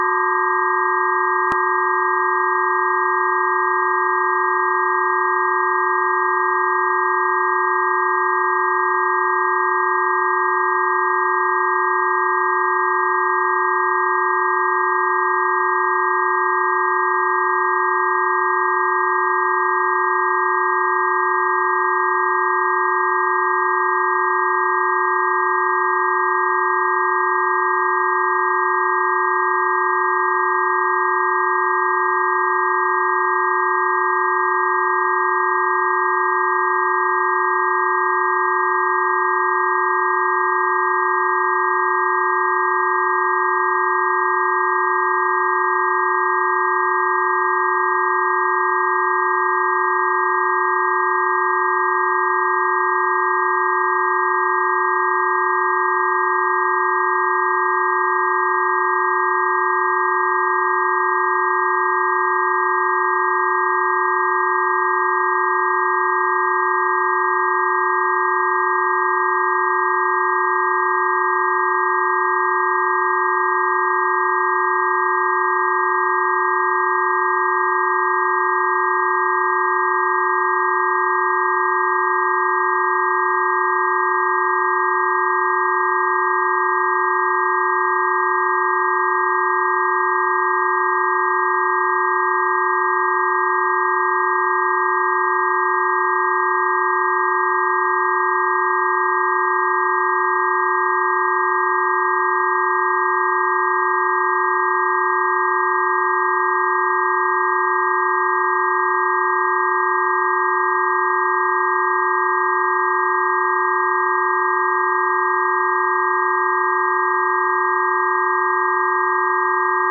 基于我发现的研究 我做了一些超声处理（将数据缩放到可听范围）以“可视化”听起来如何。 注意：这是测量数据的超声处理，而不是实际的顺势疗法补救措施。